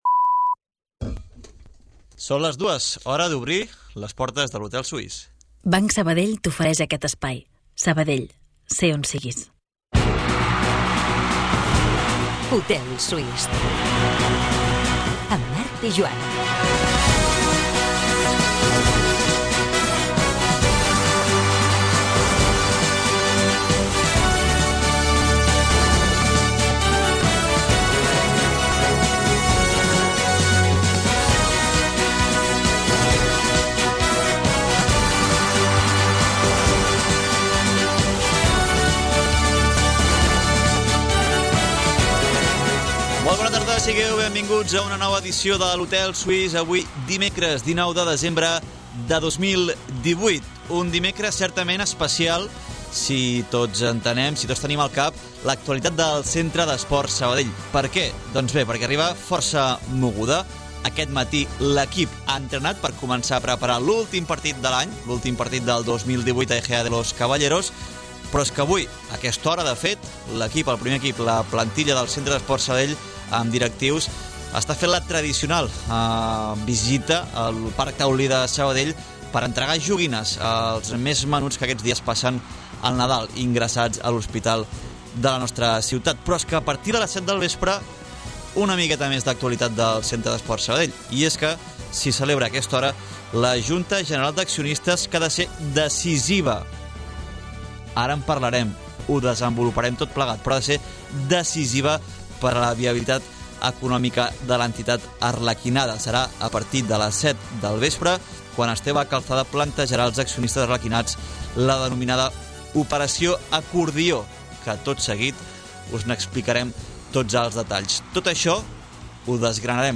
Les tertúlies esportives del recordat Hotel Suís de Sabadell prenen forma de programa de ràdio. Com passava llavors, l'hotel es converteix en l'espai reservat per a la reflexió, el debat i la conversa al voltant de l'esport de la ciutat.